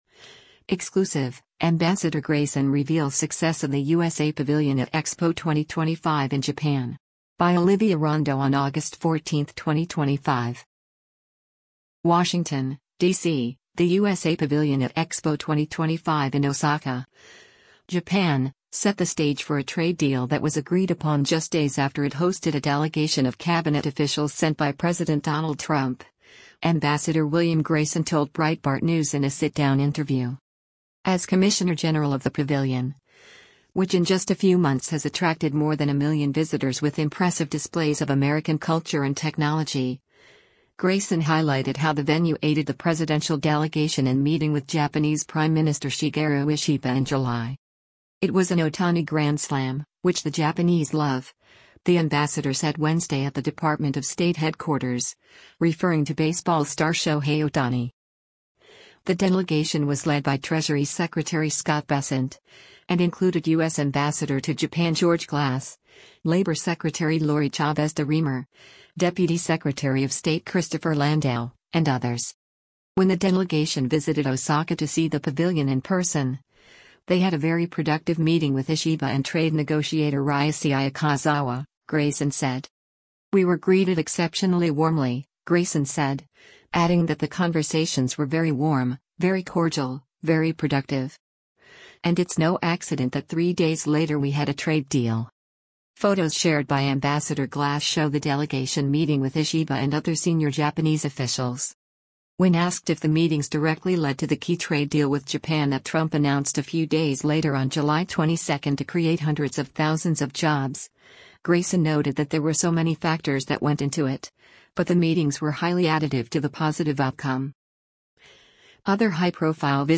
WASHINGTON, DC — The USA Pavilion at Expo 2025 in Osaka, Japan, set the stage for a trade deal that was agreed upon just days after it hosted a delegation of cabinet officials sent by President Donald Trump, Ambassador William Grayson told Breitbart News in a sit-down interview.